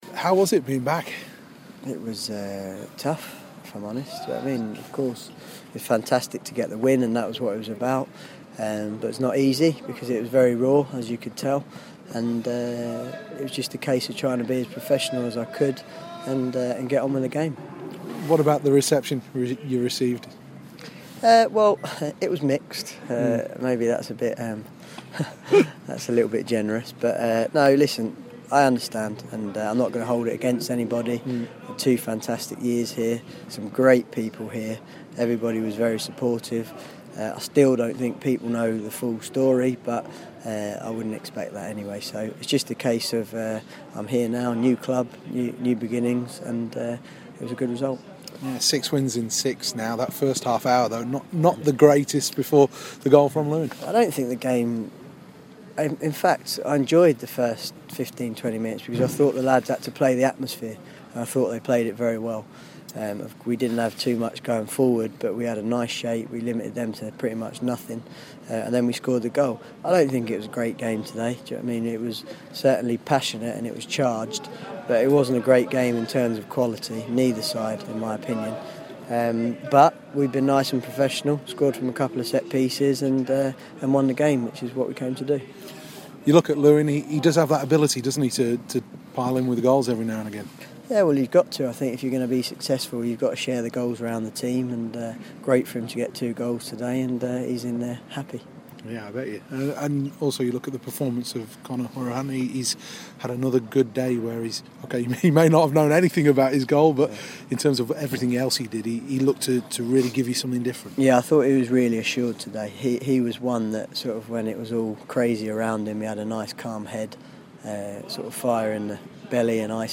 INTERVIEW: Barnsley boss Lee Johnson after the Reds 3-1 win at Oldham